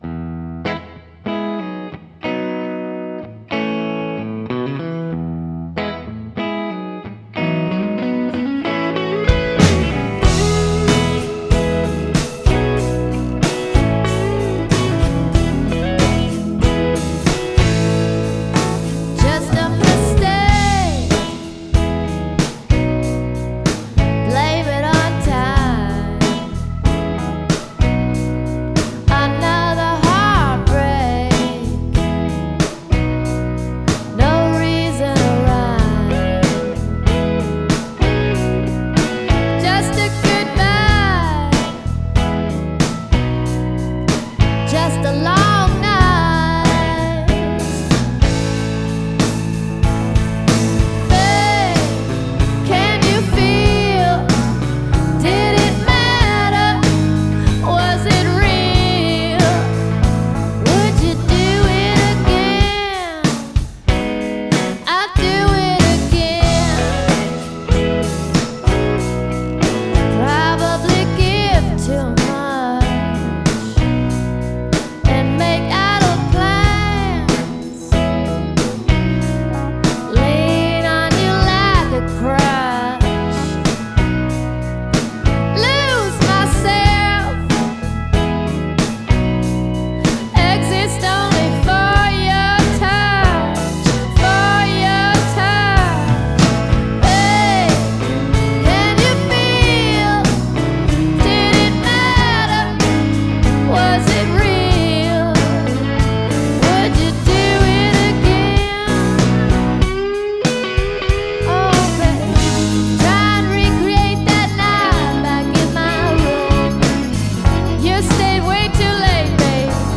blues/roots